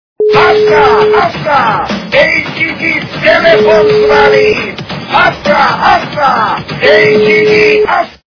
» Звуки » Смешные » Грузинский голос - Асса, асса, эй, джигит! Телефон звонит!
При прослушивании Грузинский голос - Асса, асса, эй, джигит! Телефон звонит! качество понижено и присутствуют гудки.